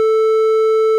clipped.wav